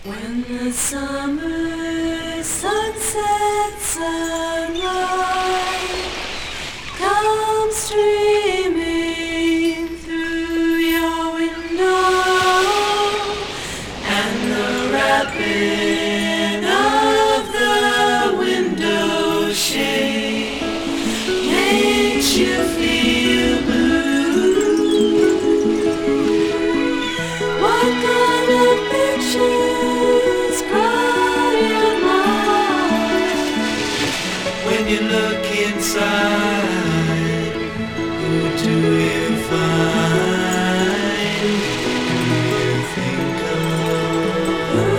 Rock, Pop, Soft Rock, Vocal　USA　12inchレコード　33rpm　Stereo